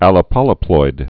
(ălə-pŏlē-ploid)